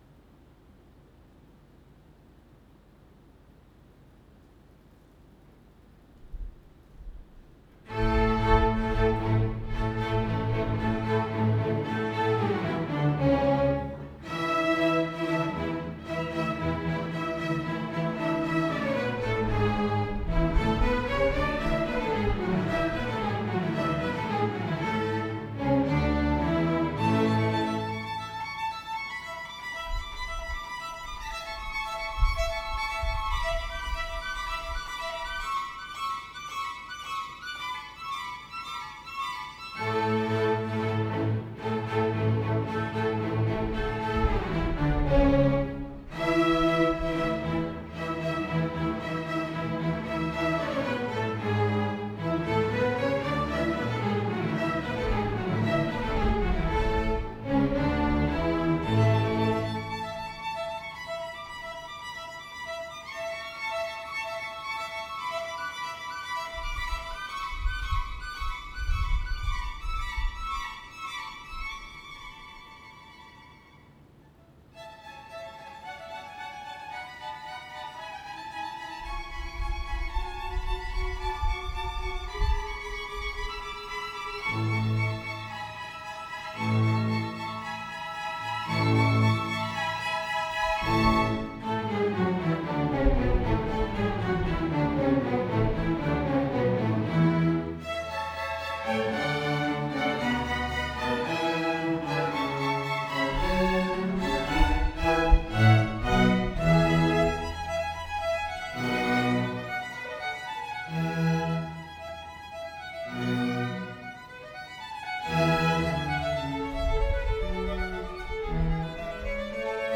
合唱部25人は夏休みからこの日に向けて練習に取り組んできました。
鹿沼市立西中学校　合唱「結」.wav
学校でとった音源をアップしてみました。